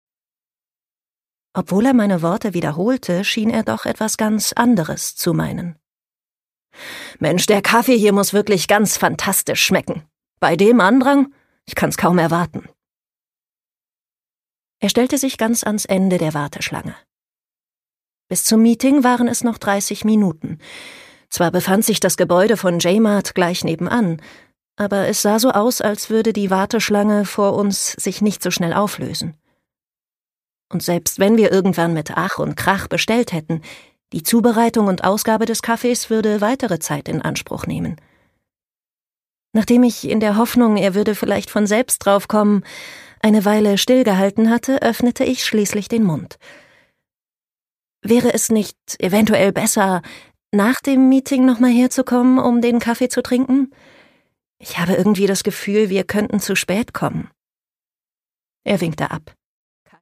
Jang Ryujin: Bis zum Mond (Ungekürzte Lesung)
Produkttyp: Hörbuch-Download